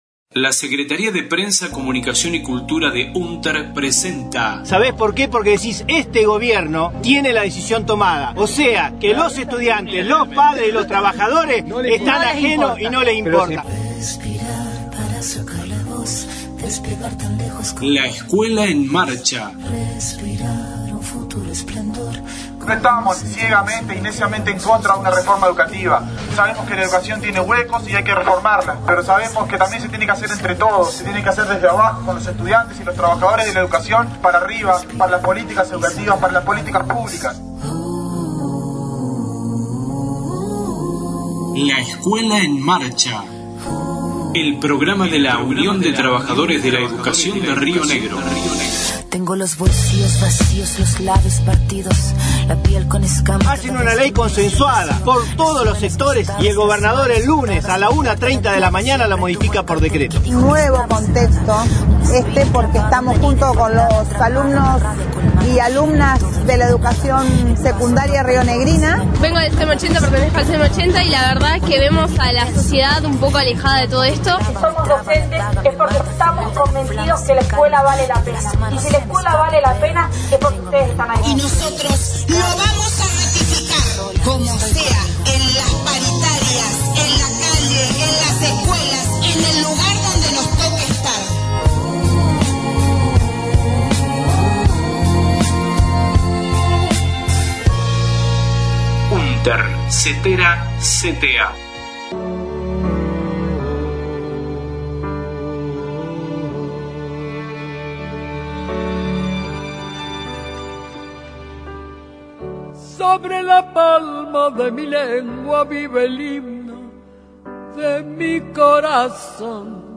en el Congreso Pedagogías y sindicato: por una educación pública para la emancipación